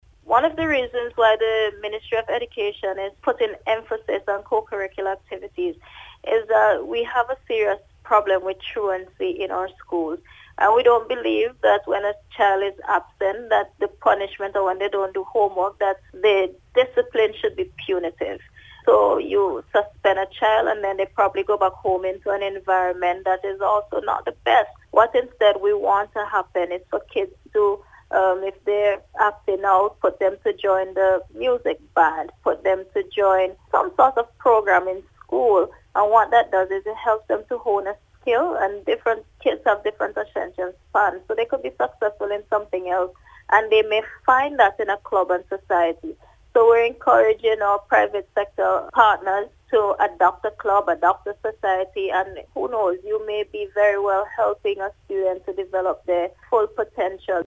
The call has come from Minister of State in the Ministry of Education, Hon. Lavern King, during an interview with NBC News.